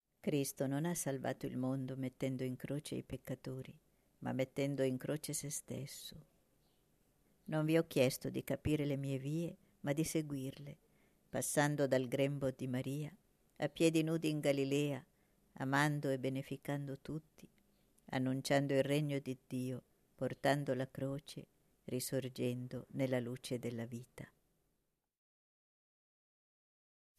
nr. 30 Preghiera mp3 Getzemani canto mp3